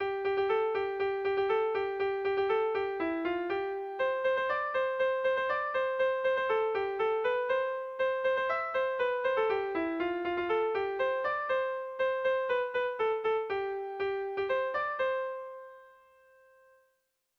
Sentimenduzkoa
Haseran eta bukaeran lelo motz bat du doinuak.
Seiko handia (hg) / Hiru puntuko handia (ip)
ABD